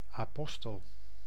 Ääntäminen
Synonyymit voorvechter Ääntäminen Tuntematon aksentti: IPA: /ɑˈpɔstəl/ Haettu sana löytyi näillä lähdekielillä: hollanti Käännös 1. lärjunge {en} 2. apostel {en} Suku: m .